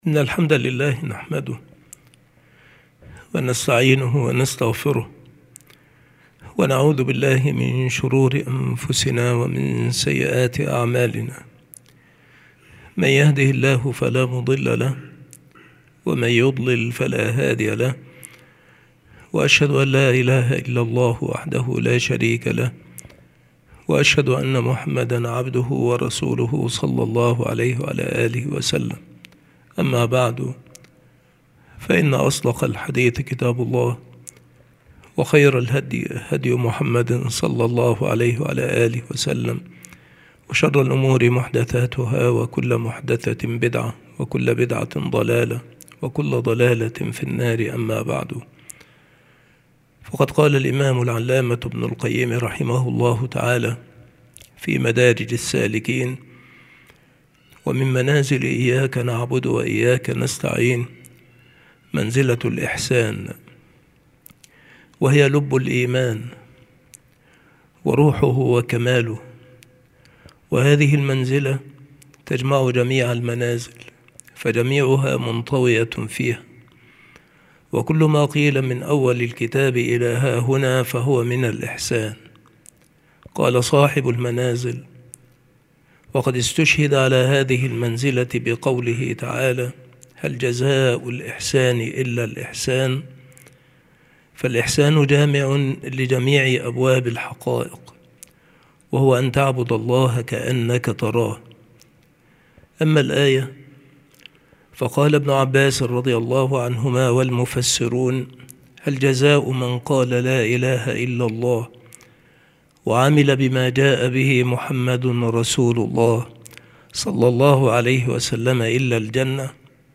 مكان إلقاء هذه المحاضرة المكتبة - سبك الأحد - أشمون - محافظة المنوفية - مصر عناصر المحاضرة : منزلة الإحسان. درجات الإحسان. لزوم العبد للإحسان في سائر أحواله. تمييز الوارد الرحماني عند أولي البصائر والعلم. لله على كل قلب هجرتان.